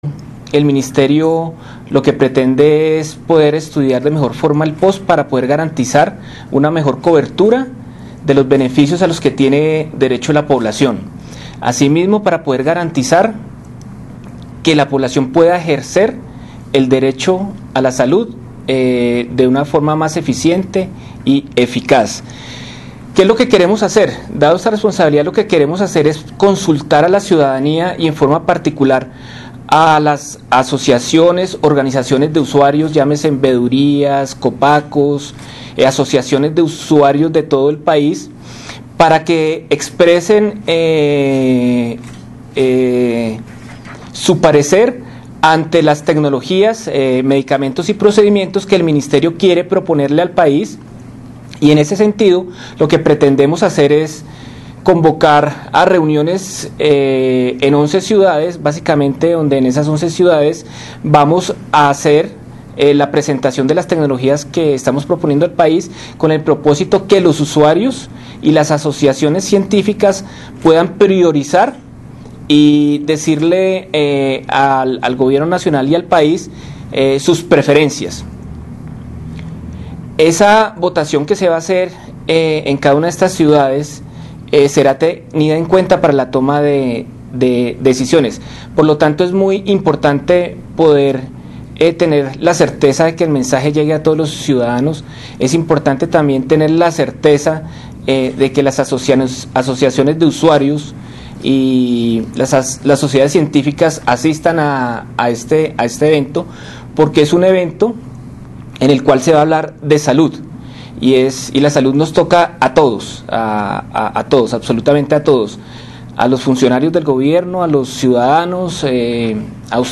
Audio, Félix Nates, Director Regulación habla sobre Actualización del POS